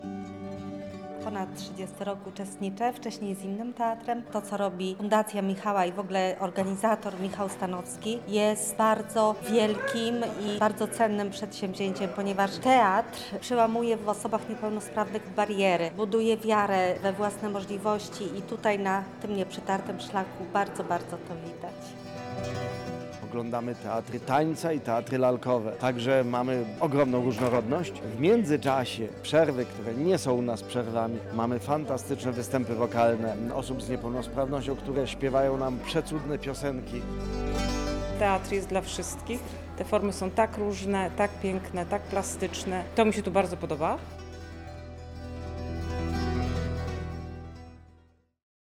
Więcej o wydarzeniu opowiedzieli nam jego uczestnicy.